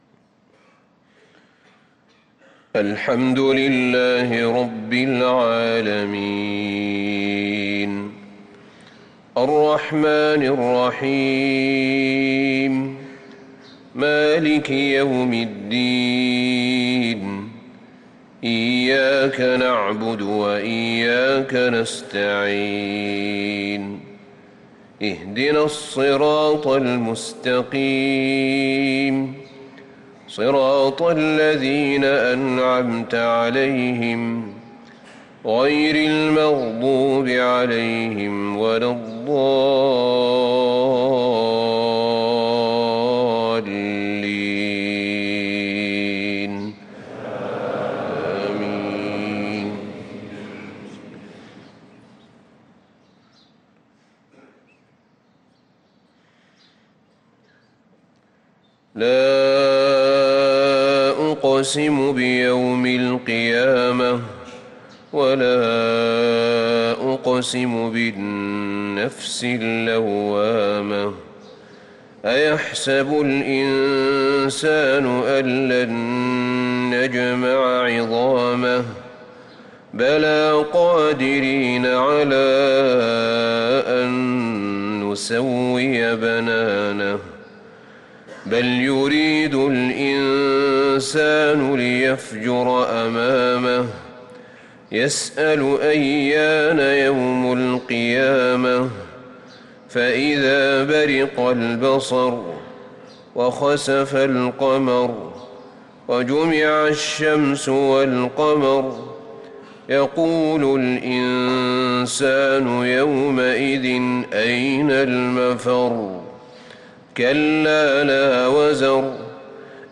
صلاة الفجر للقارئ أحمد بن طالب حميد 10 ربيع الأول 1445 هـ